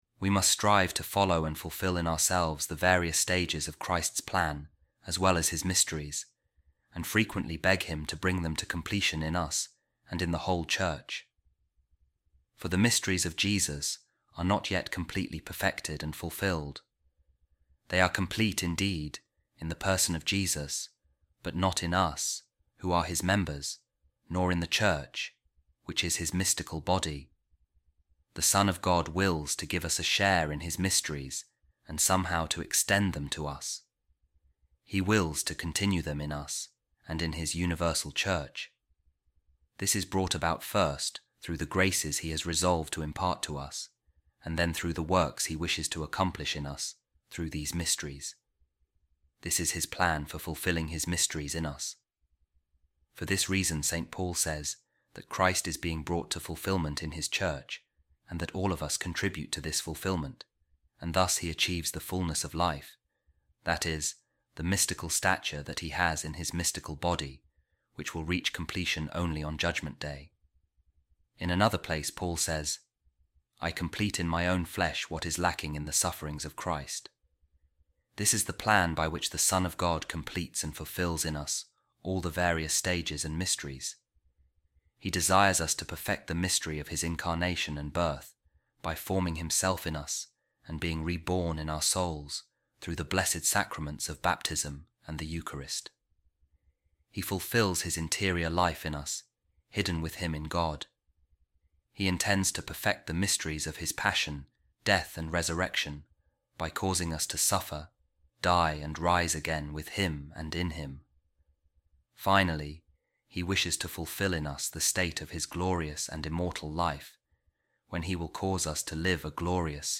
A Reading From The Treatise Of Saint John Eudes On The Kingdom Of Jesus | The Mystery Of Christ In Us And In The Church